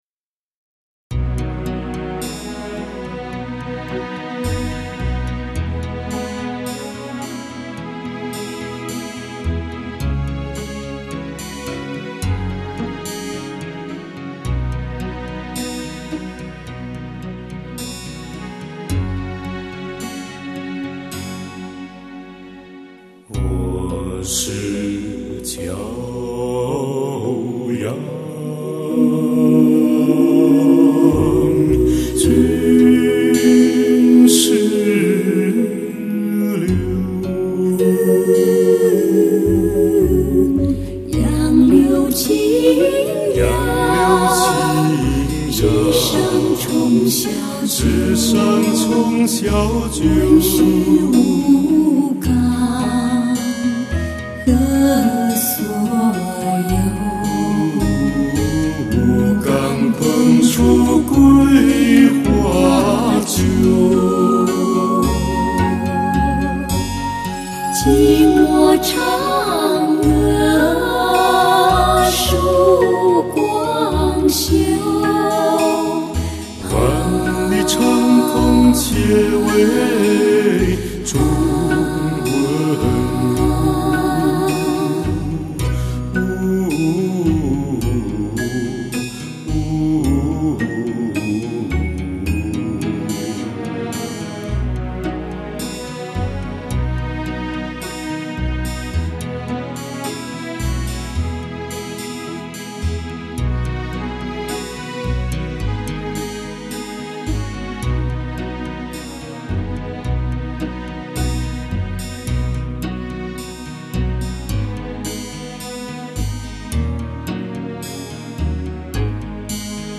男女声二重唱